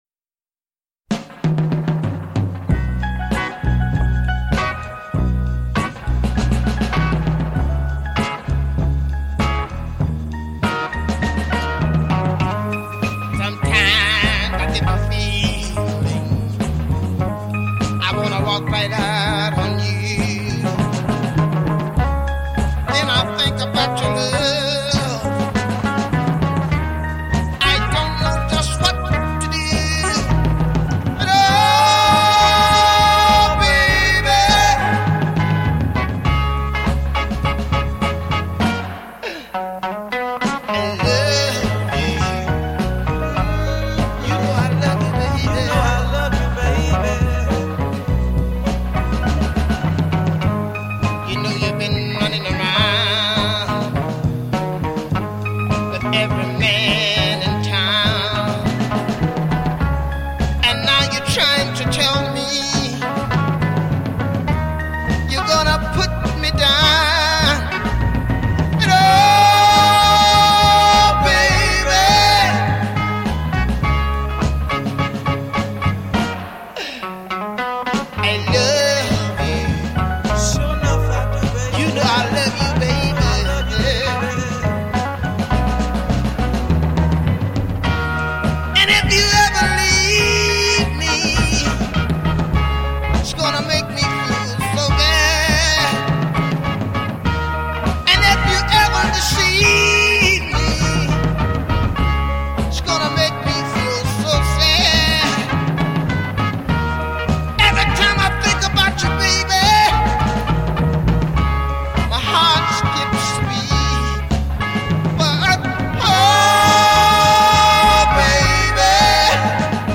is a #1 soul stunner
Just extraordinary soul on display here.